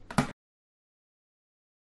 Descarga de Sonidos mp3 Gratis: boton 4.
button-push-sound.mp3